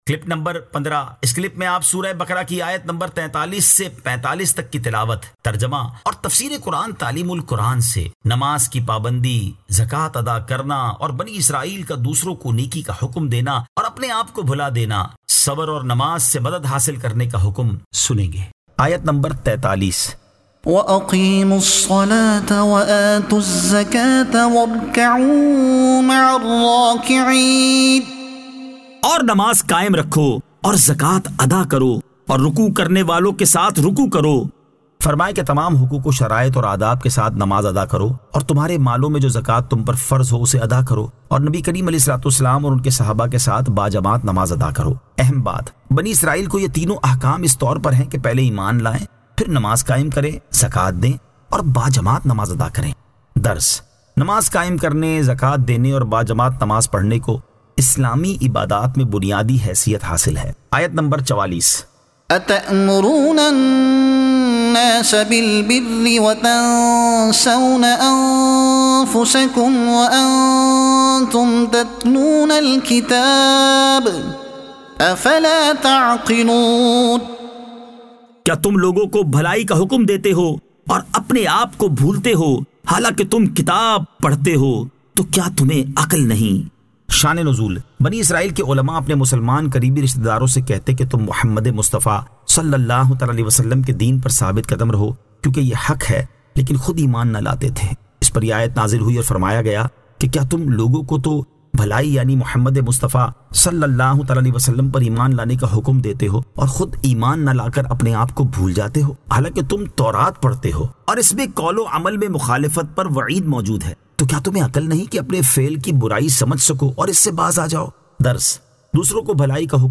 Surah Al-Baqara Ayat 43 To 45 Tilawat , Tarjuma , Tafseer e Taleem ul Quran